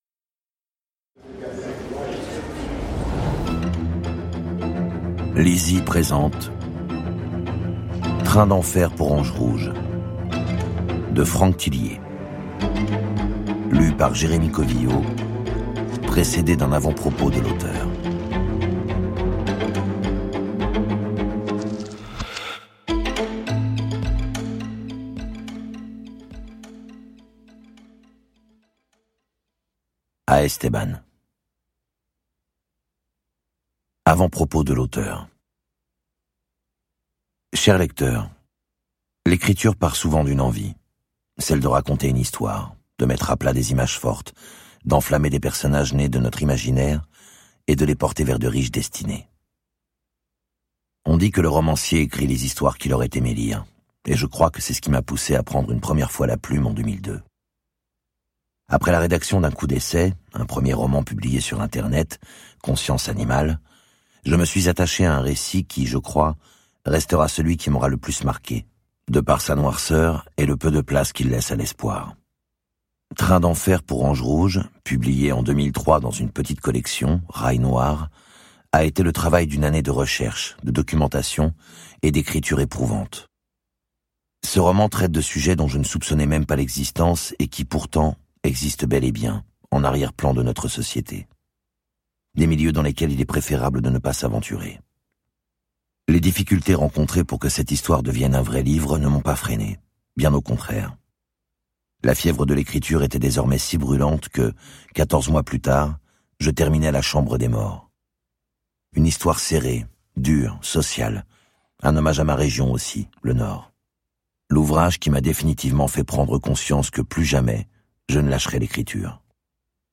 Extrait gratuit